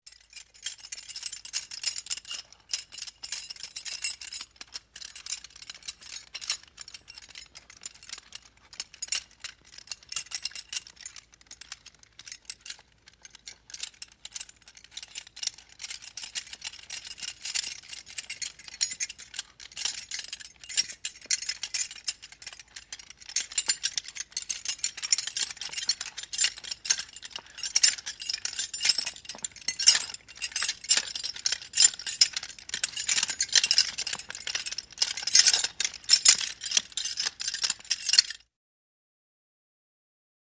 Keys Jingle; Metal Key Movement, Clanks And Low Jingling. Heavy.